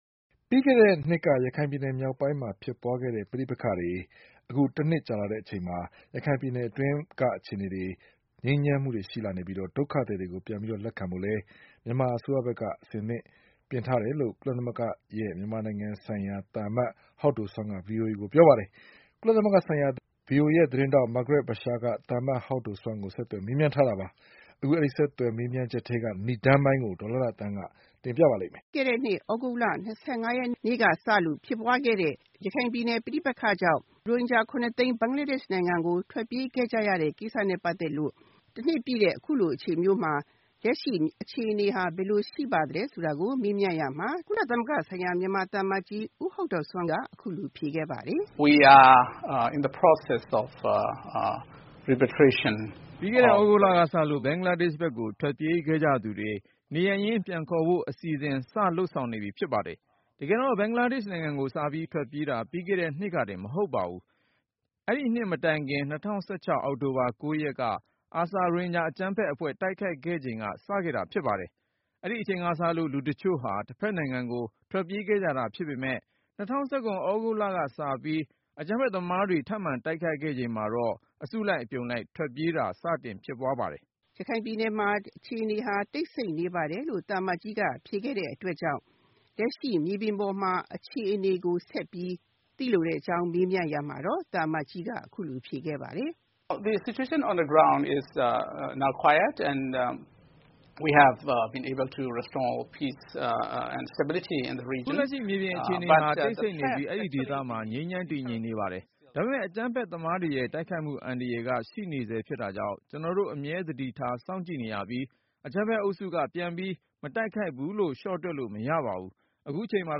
ရိုဟင်ဂျာပဋိပက္ခ တနှစ်ပြည့်ချိန် ကုလ မြန်မာသံအမတ်ကြီးနဲ့ VOA မေးမြန်းခန်း